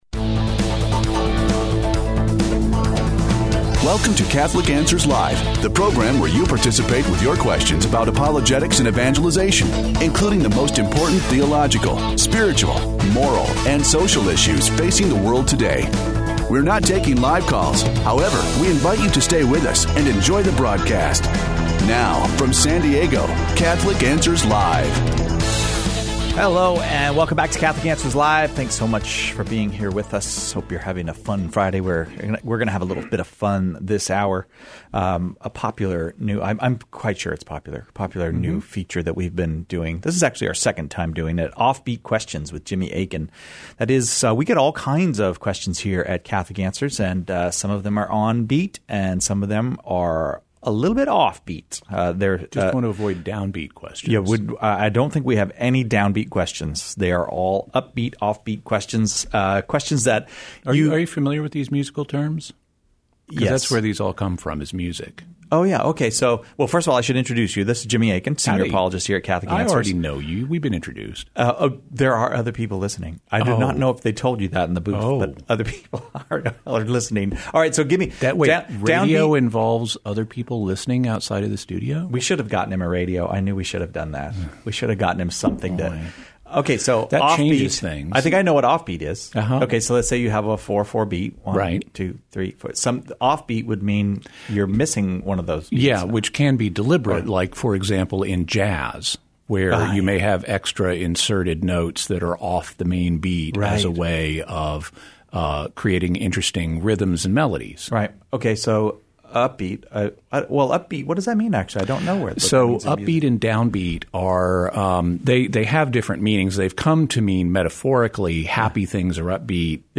Offbeat Questions (Pre-recorded)